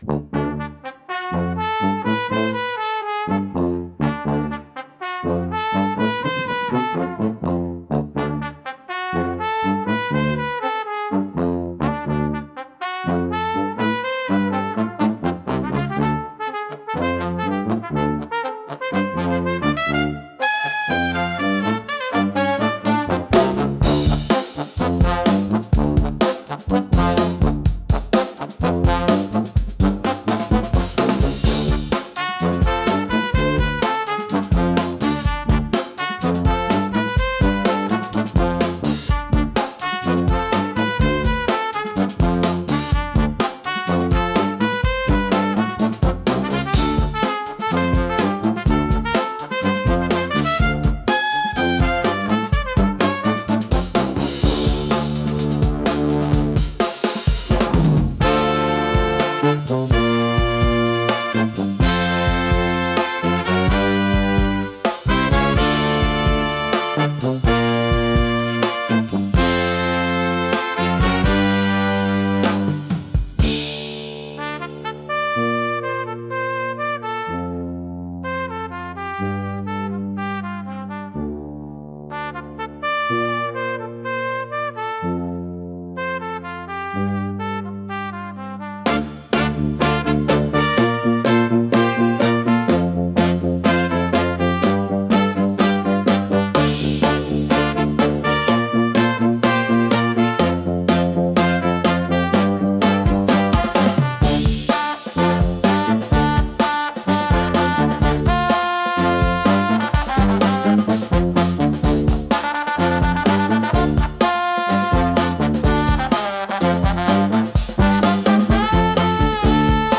一度聴いたら忘れることのできないシンプルな旋律を吹奏楽アレンジしてみました。